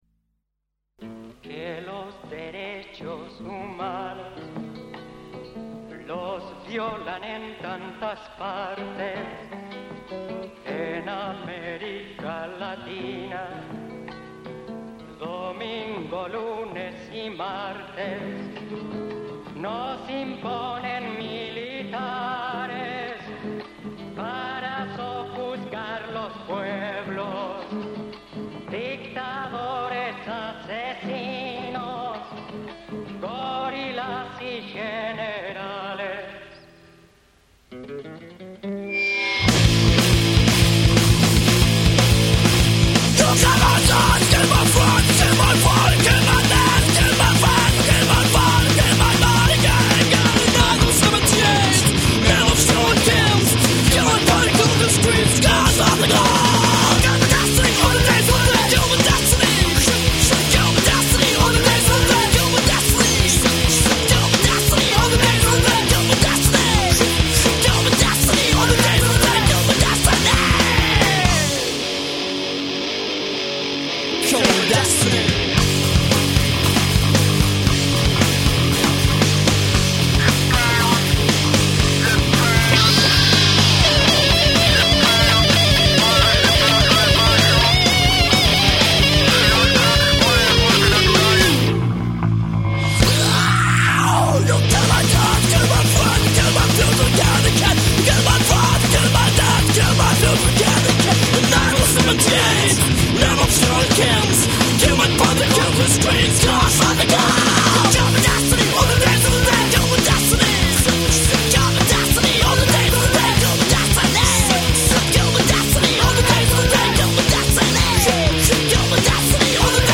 guitarra
bajo
bateria